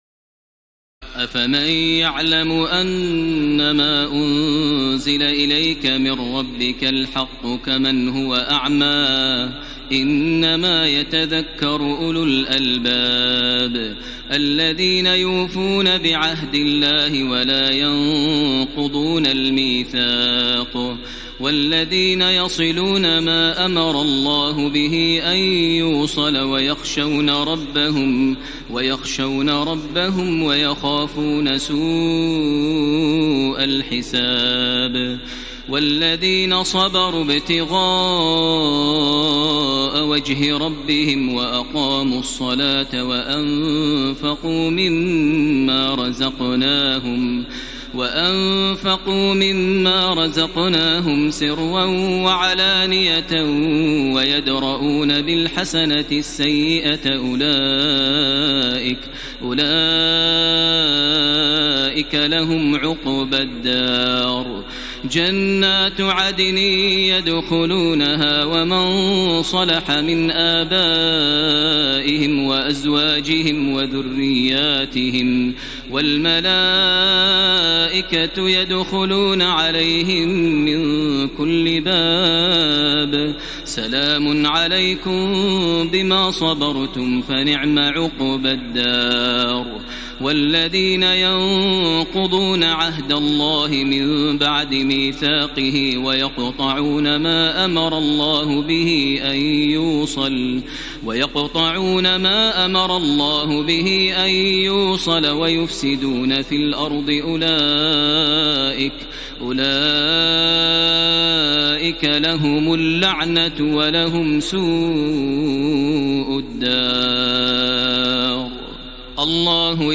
تراويح الليلة الثانية عشر رمضان 1433هـ من سورتي الرعد (19-43) و إبراهيم كاملة Taraweeh 12 st night Ramadan 1433H from Surah Ar-Ra'd and Ibrahim > تراويح الحرم المكي عام 1433 🕋 > التراويح - تلاوات الحرمين